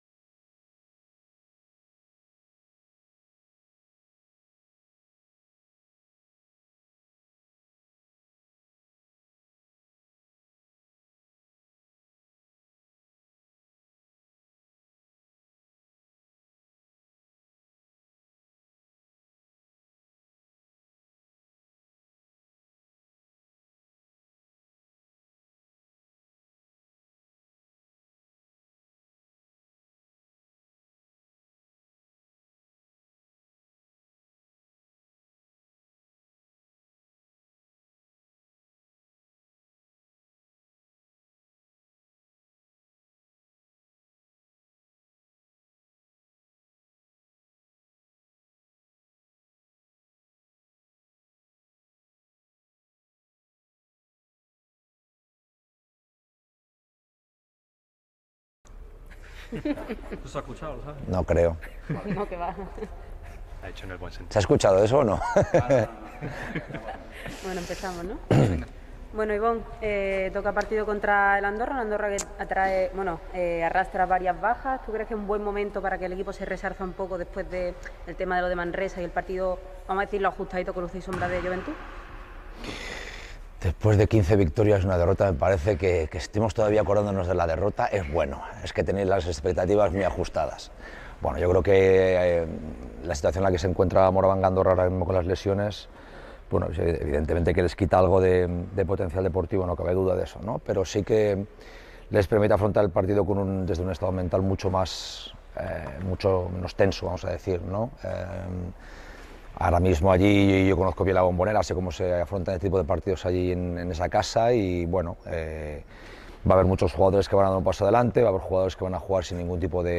El técnico cajista ha comparecido ante los medios en la previa del duelo que enfrentará a los malagueños contra el Morabanc Andorra el próximo domingo a las 17:00 horas. El preparador habla sobre el estado del equipo, dudas de cara al choque como la de Kravish, la alteración que ha ocasionado la DANA en la planificación y la mentalidad de campeón que comienza a implantsarse en el equipo.